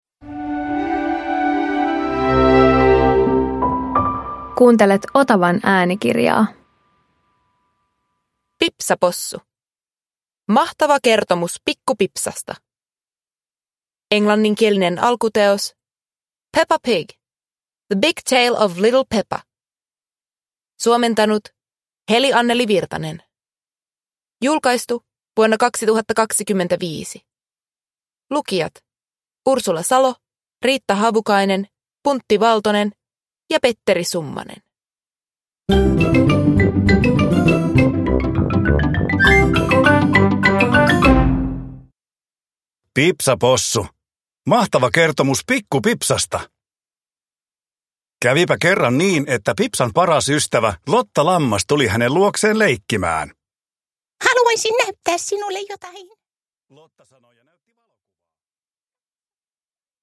Pipsa Possu - Mahtava kertomus pikku Pipsasta – Ljudbok